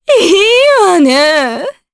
Maria-Vox_Happy4_jp.wav